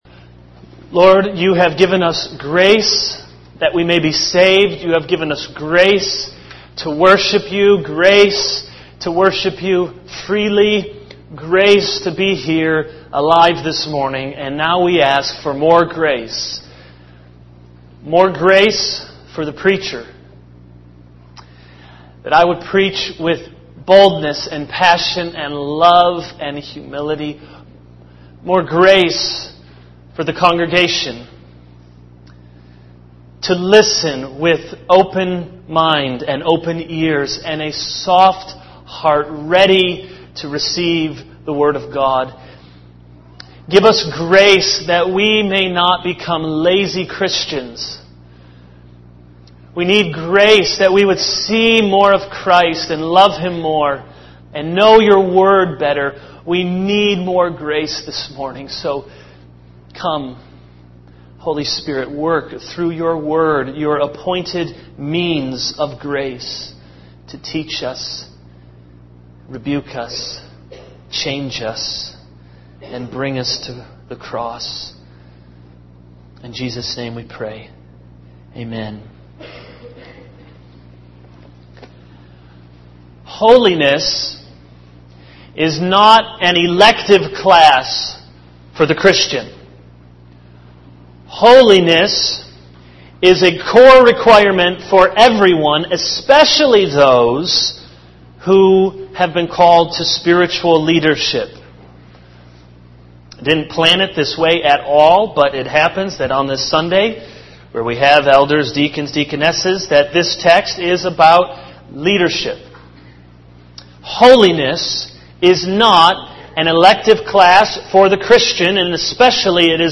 All Sermons Holiness & God’s Leaders 0:00 / Download Copied!